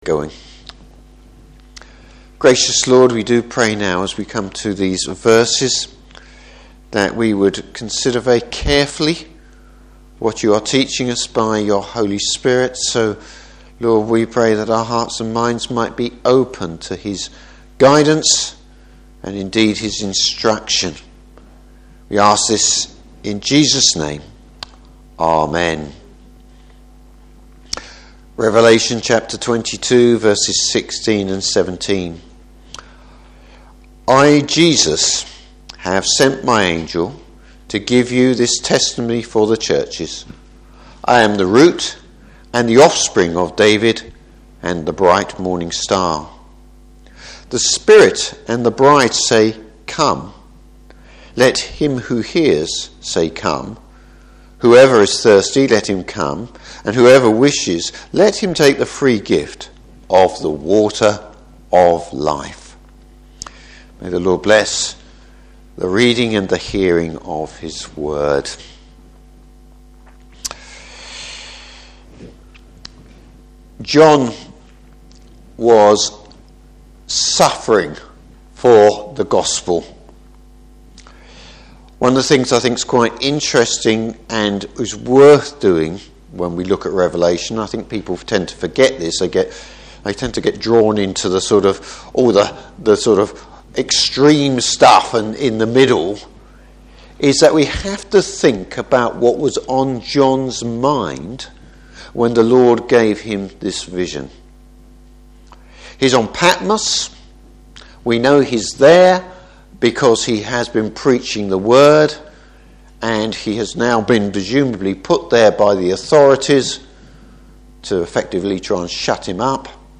Service Type: Morning Service Jesus’ last words in scripture.